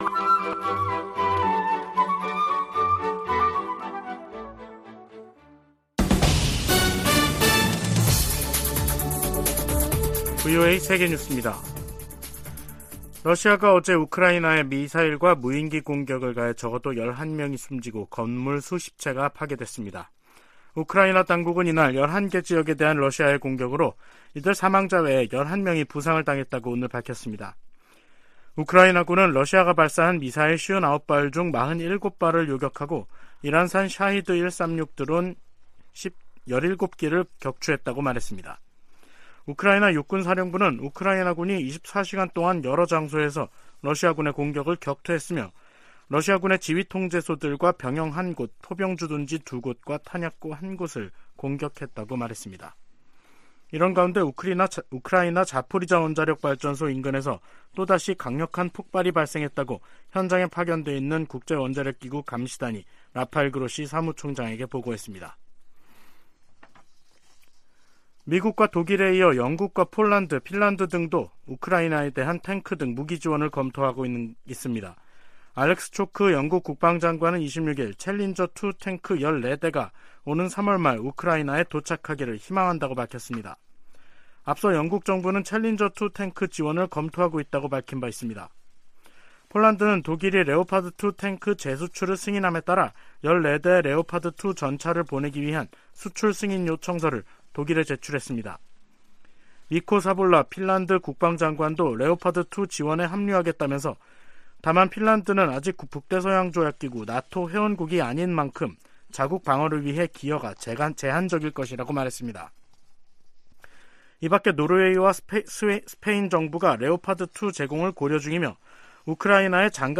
VOA 한국어 간판 뉴스 프로그램 '뉴스 투데이', 2023년 1월 27일 3부 방송입니다. 미국과 한국의 국방장관들이 31일 서울에서 회담을 갖고 대북정책 공조, 미국 확장억제 실행력 강화 등 다양한 동맹 현안들을 논의합니다. 미국 정부가 러시아 군사조직 바그너 그룹을 국제 범죄조직으로 지목하고 현행 제재를 강화했습니다.